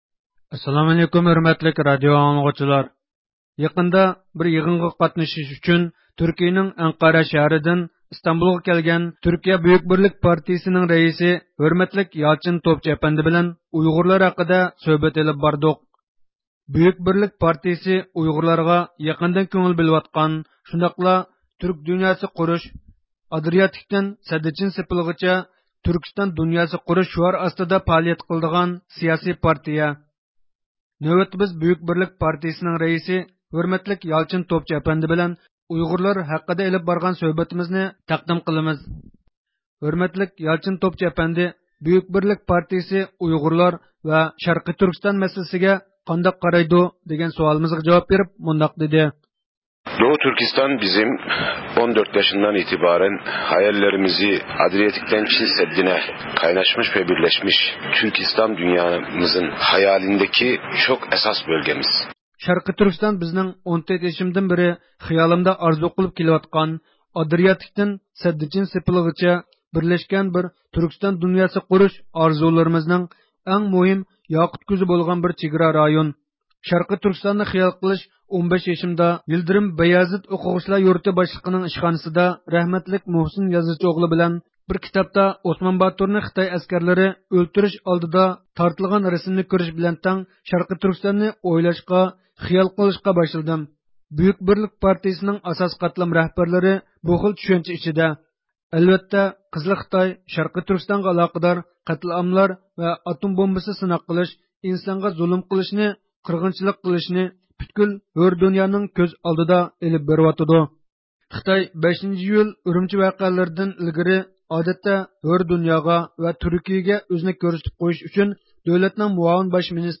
يېقىندا، يىغىنغا قاتنىشىش ئۈچۈن تۈركىيىنىڭ ئەنقەرە شەھىرىدىن ئىستانبۇلغا كەلگەن تۈركىيە بۈيۈك بىرلىك پارتىيىسىنىڭ رەئىسى ھۆرمەتلىك يالچىن توپچۇ ئەپەندى بىلەن ئۇيغۇرلار ھەققىدە سۆھبەت ئېلىپ باردۇق.